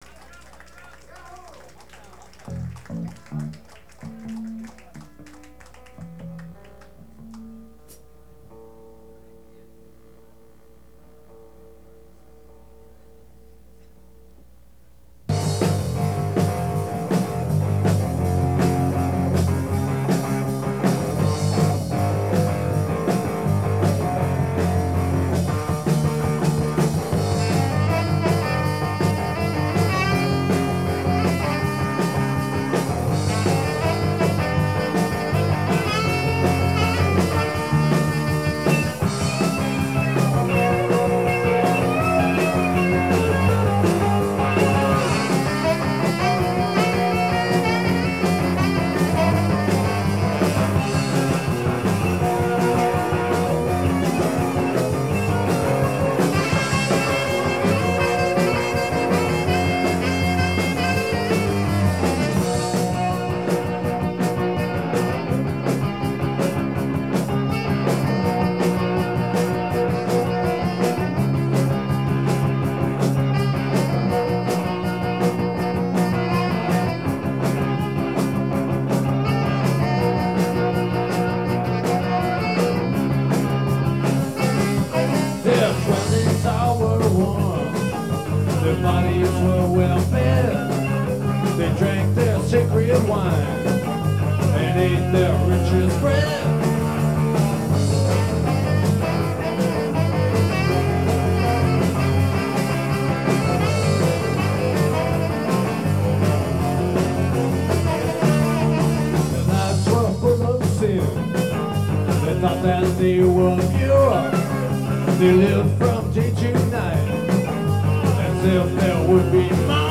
bass, backing vocals
sax, backing vocals
guitar, vocals